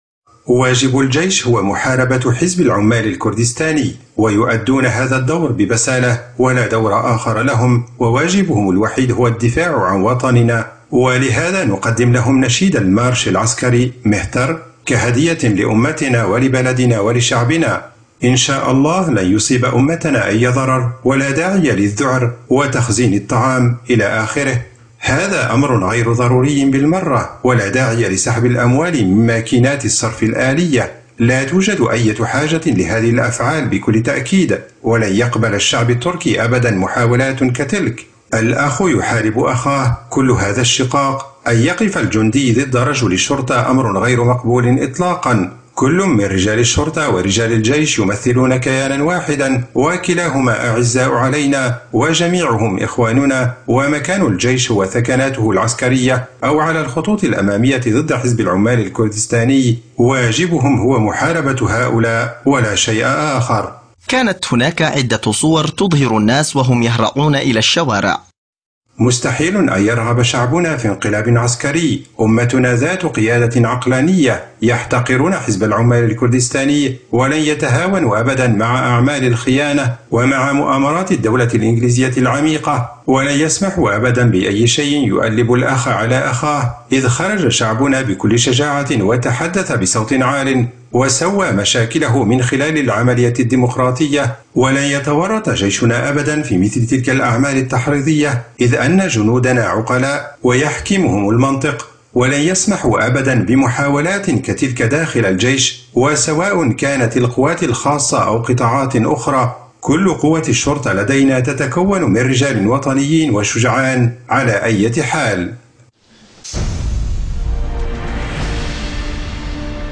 مُقتطف من البث الحي لحوار عدنان أوكطار على قناة A9TV بتاريخ 15 يوليو 2016 عدنان أوكطار: واجب الجيش هو محاربة حزب العمال الكردستاني، ويؤدون هذا...